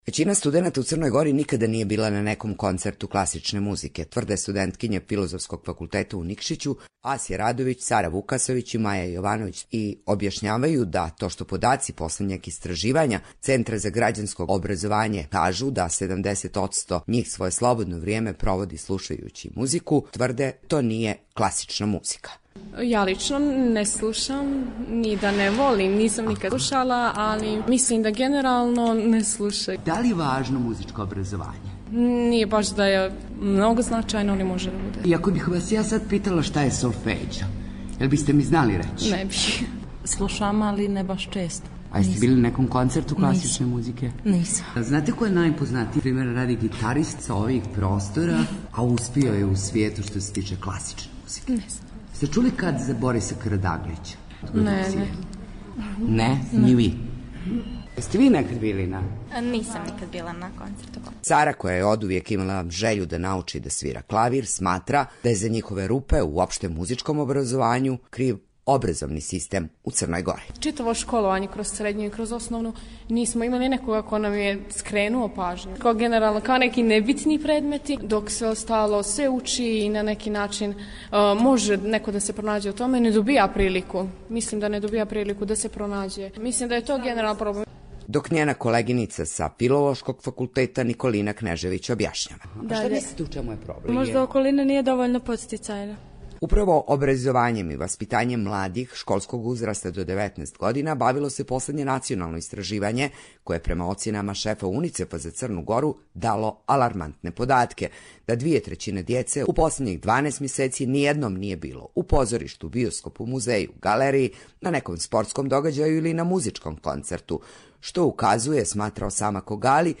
U ovosedmičnoj emisiji pitali smo mlade iz BiH, Srbije i Crne Gore da li slušaju i koliko često odlaze na koncerte klasične muzike?